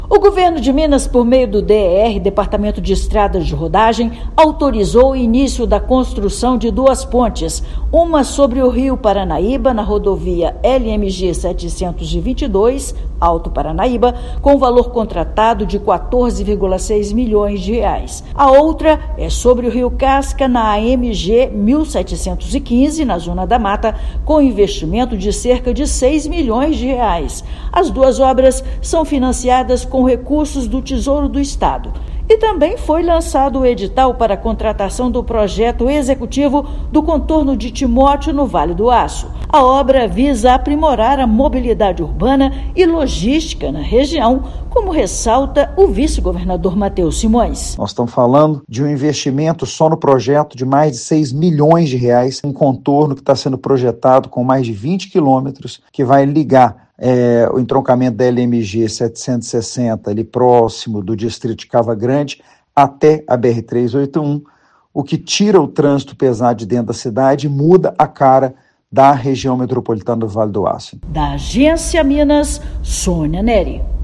Estado lançou edital para execução do projeto do Contorno de Timóteo e autorizou a construção de ponte na LMG-722 e na AMG - 1715. Ouça matéria de rádio.